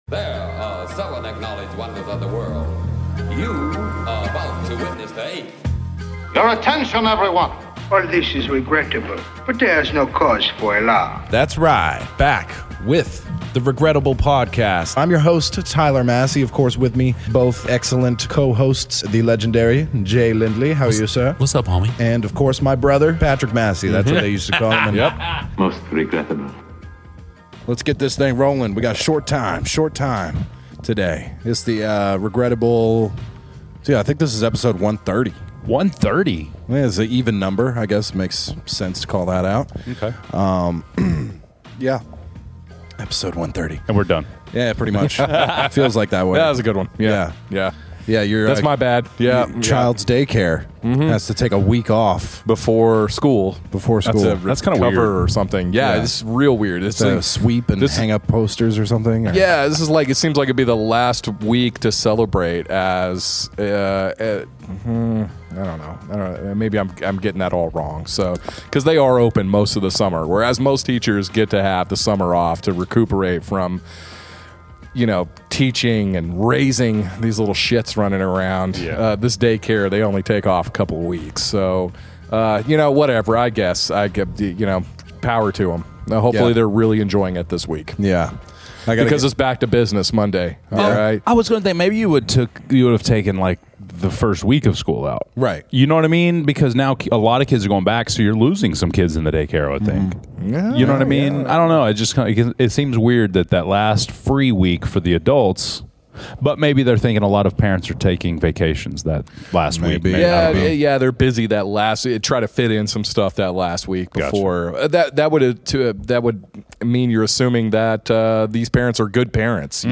Disclaimer: Strong language, Sensitive subjects.